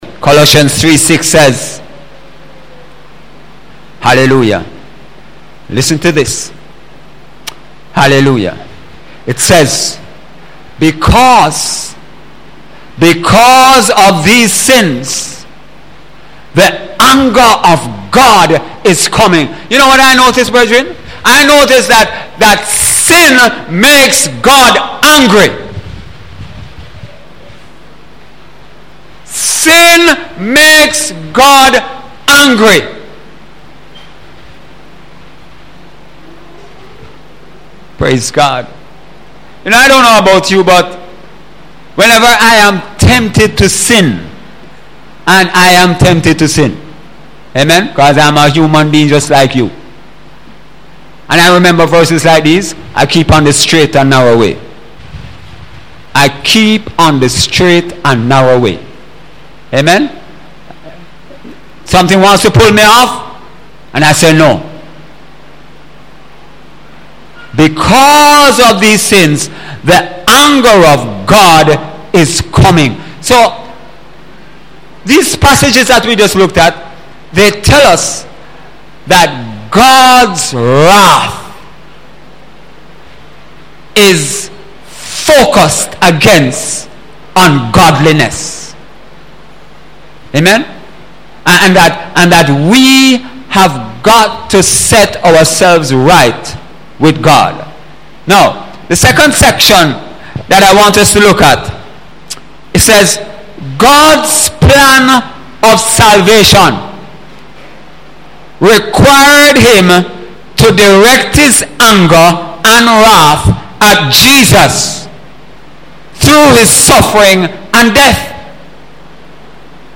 Sunday sermon – May 21, 2017 – God’s wrath demands suffering and death